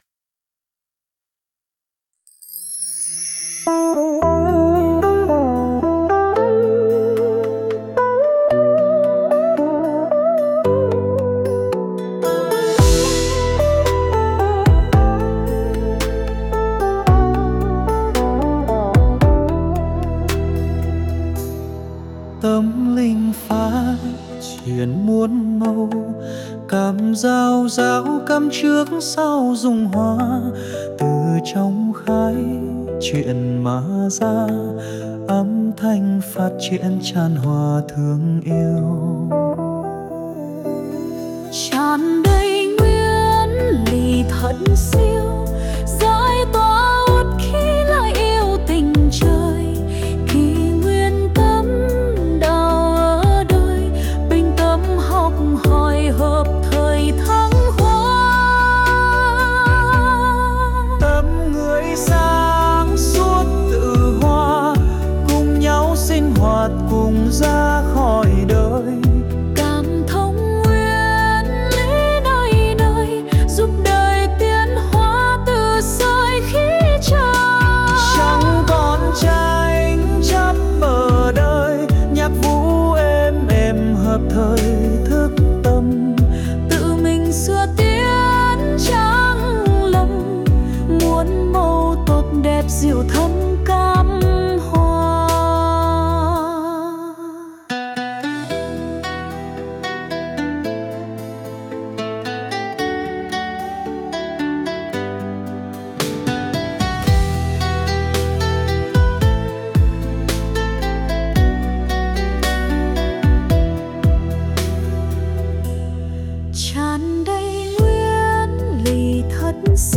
241-Muon-mau-02-nam-nu.mp3